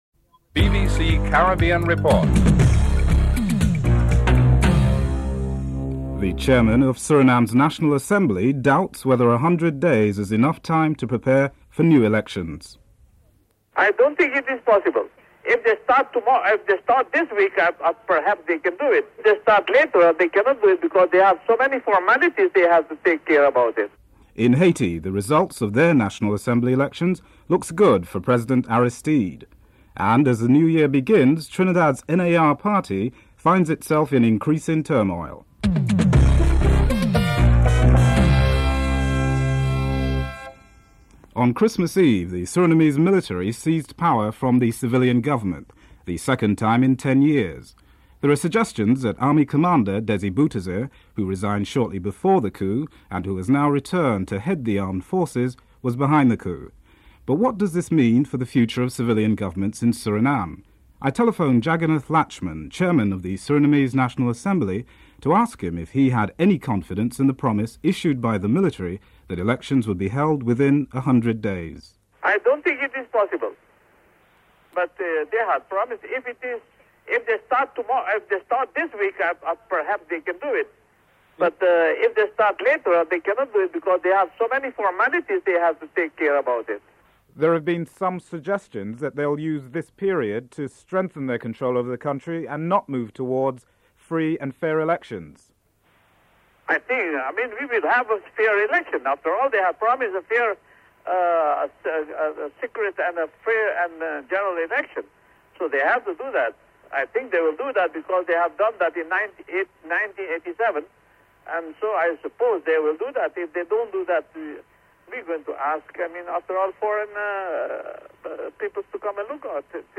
She is quite upset and believes that the image of Caribbean countries has been tarnished as one of its partners changes its method of governance.